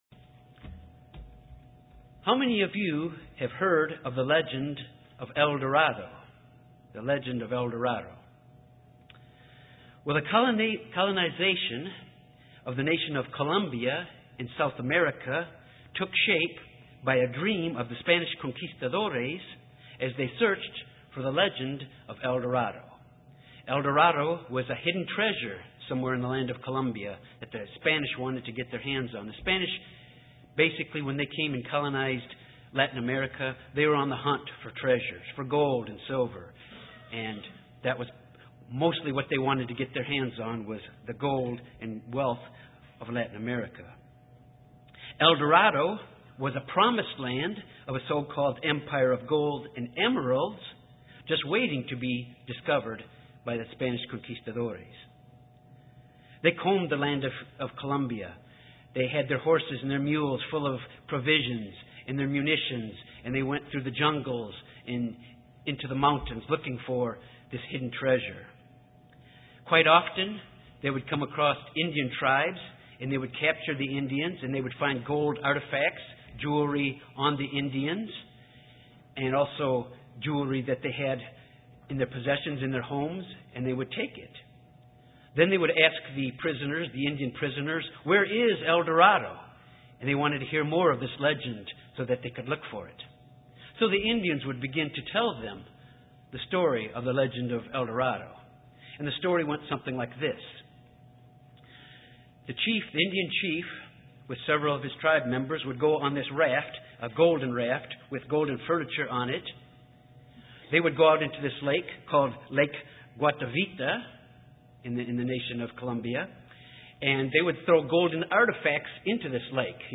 Sermons
Given in Little Rock, AR Jonesboro, AR